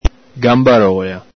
Ve - Cent.